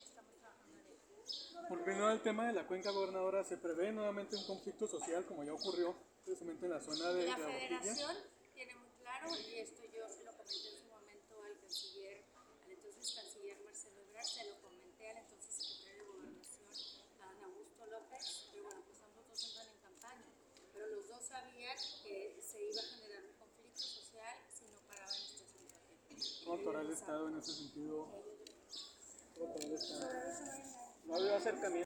‘Sobre aviso no hay engaño’ advirtió la gobernadora del Estado, Maru Campos, sobre los posibles conflictos sociales en los que pueda desencadenar la extracción de presas para subsidiar el déficit de agua en los estados de Tamaulipas y Nuevo León.